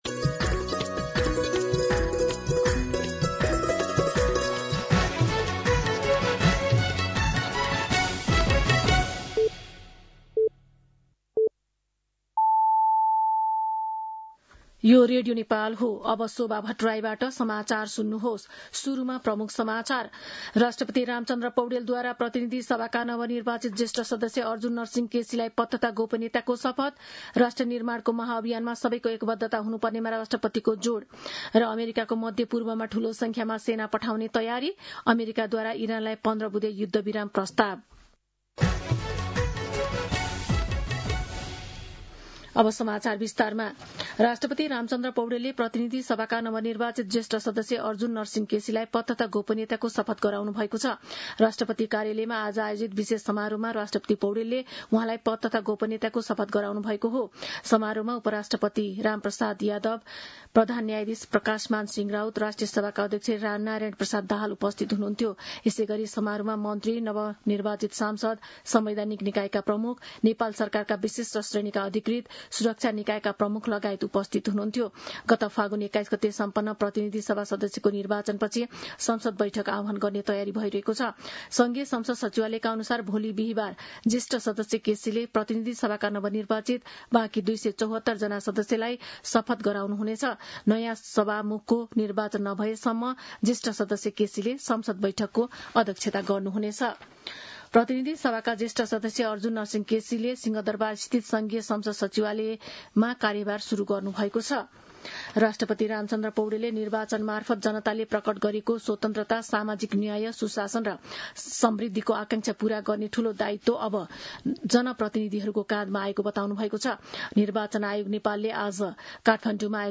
दिउँसो ३ बजेको नेपाली समाचार : ११ चैत , २०८२
3pm-Nepali-News-3.mp3